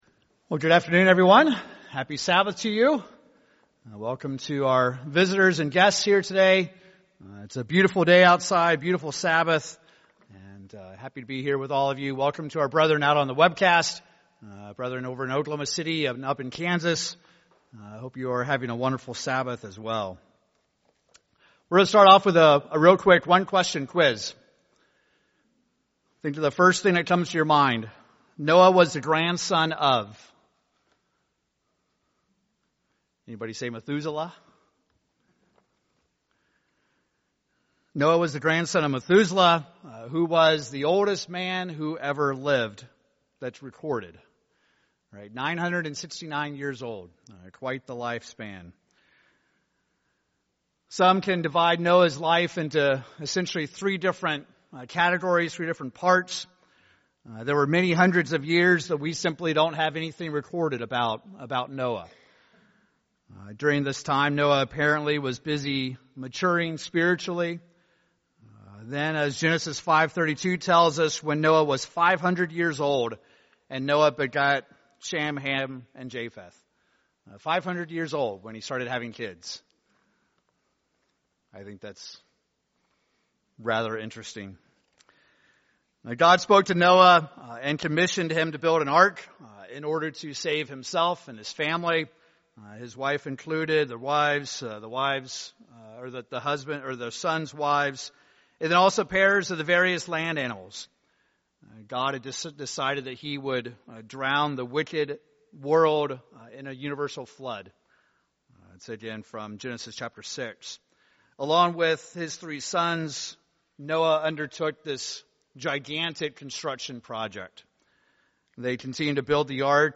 Are we today required to be keeping God's law in order to be righteous, or can this important characteristic be achieved in some other way? In this sermon we will examine four specific statements from Jesus Christ about what it means to be righteous.